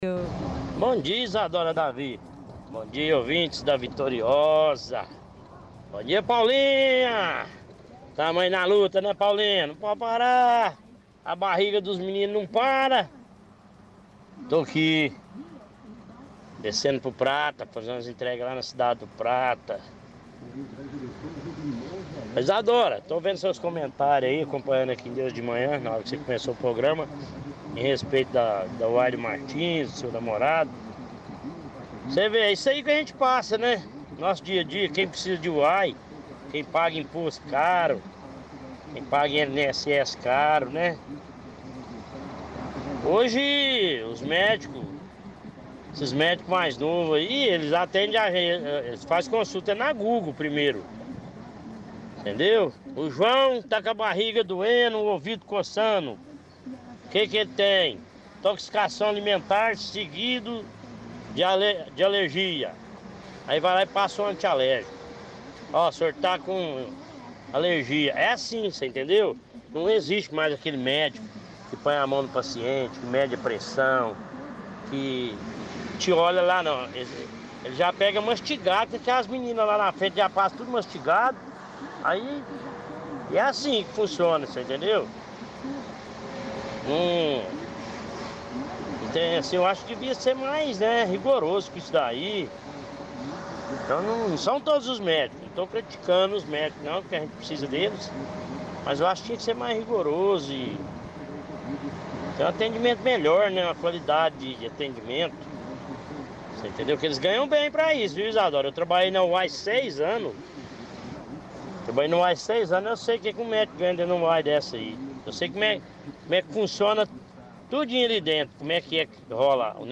– Outra ouvinte também reclama dos atendimentos de médicos de Uberlândia, cita o HC da UFU, UAIs e UBSFs.
– Outra ouvinte fala que levou a filha no UAI Pampulha, ela estava passando mal.